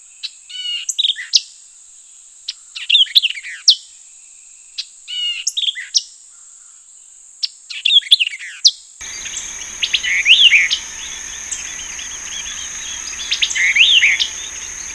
White-eyed Vireo
Vireo griseus
A veces canta en el invierno.